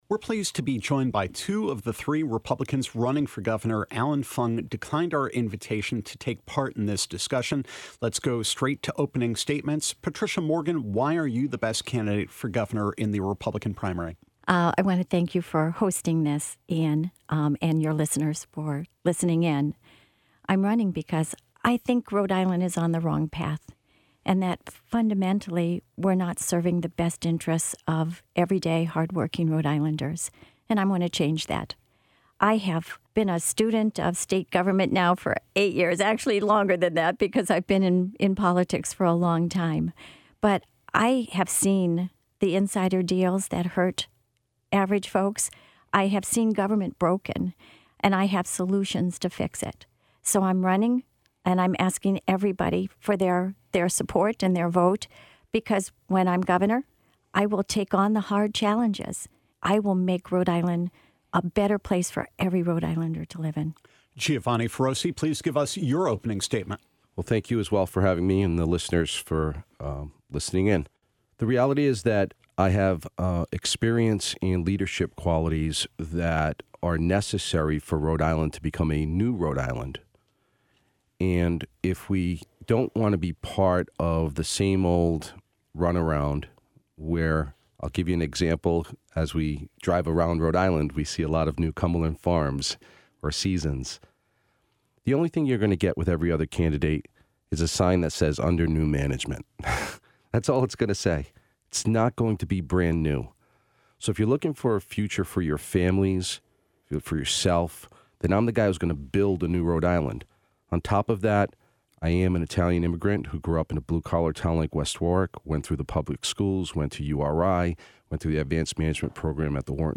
Rhode Island Public Radio presents a special primary debate in Rhode Island’s Republican race for governor.
We invited all three candidates into our studio for an in-depth look at where they stand on the issues; Fung declined our invitation.